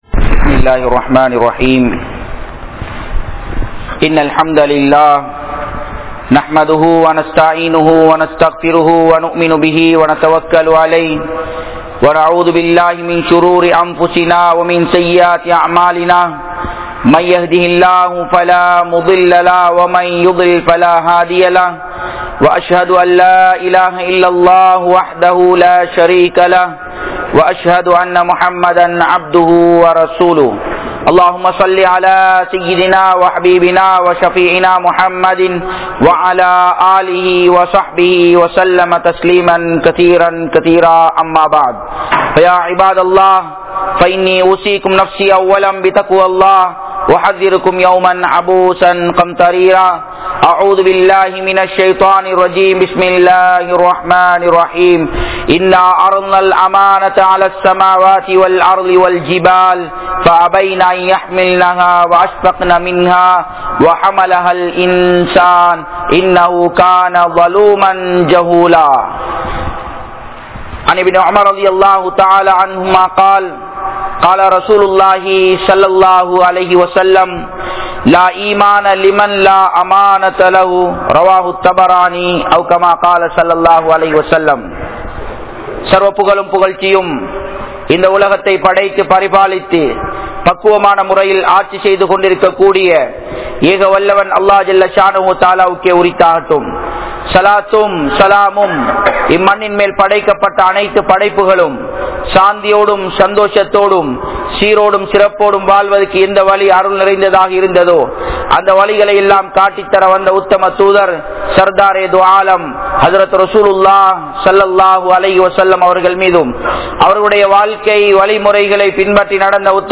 Manithanudaiya Kadamaihal Enna? (மனிதனுடைய கடமைகள் என்ன?) | Audio Bayans | All Ceylon Muslim Youth Community | Addalaichenai